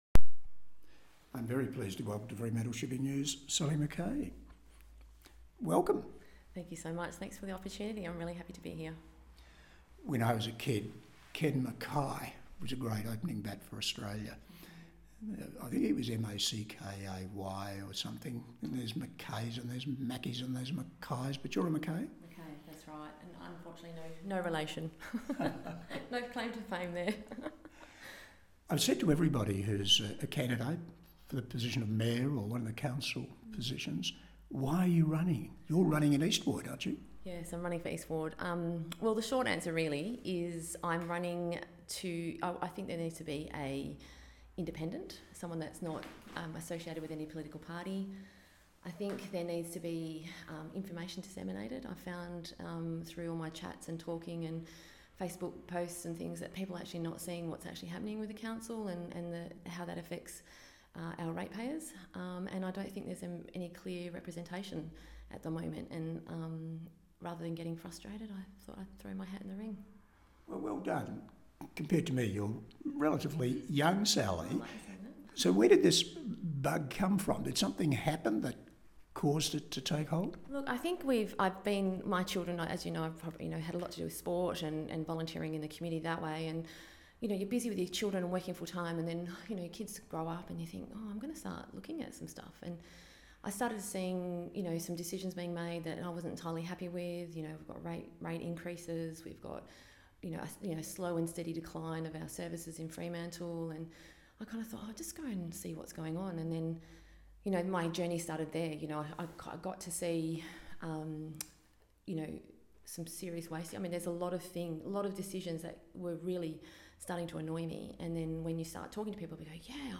The East Ward Candidates Interviews